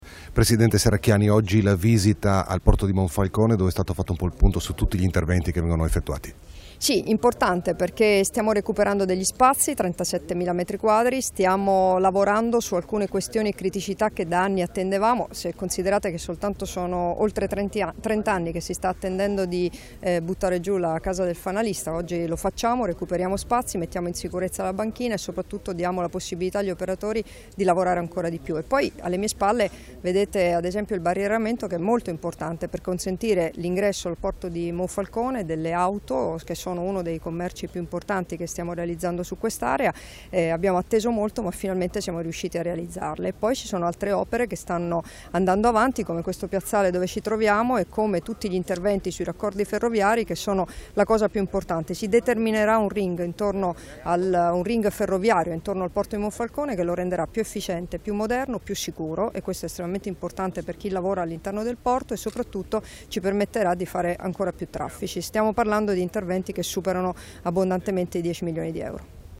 Dichiarazioni di Debora Serracchiani (Formato MP3) [1120KB]
a margine della visita al Porto, rilasciate a Monfalcone il 13 aprile 2017